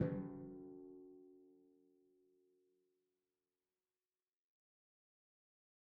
Timpani Large
Timpani5_Hit_v3_rr1_Sum.mp3